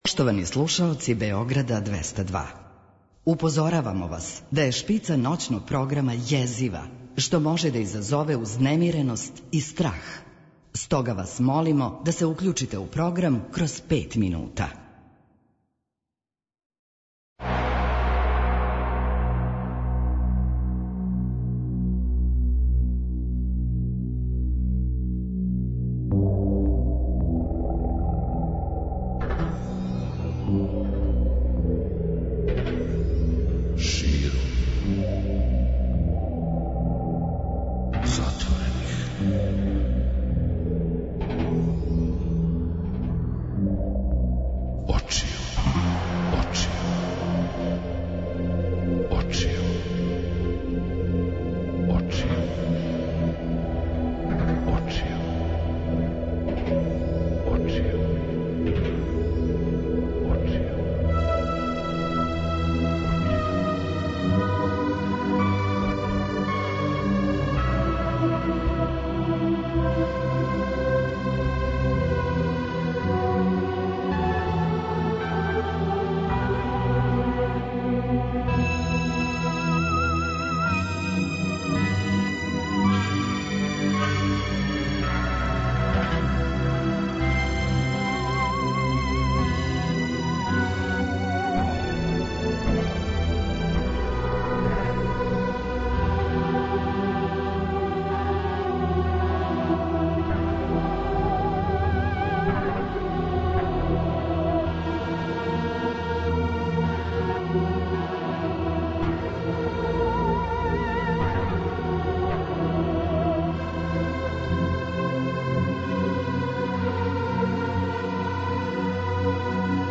Вечерас у емисији Још овај пут ноћног програма Београда 202 дружићемо се са Вама до раних јутарњих сати у једном лаганом, опуштеном размишљању шта нам то кроји овај наш мали а тако вредан и један живот.